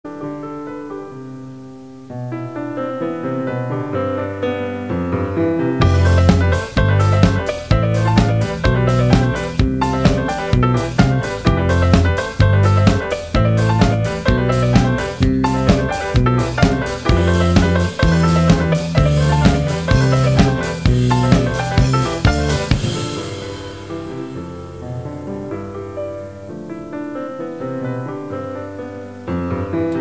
pianoforte
contrabbasso
batteria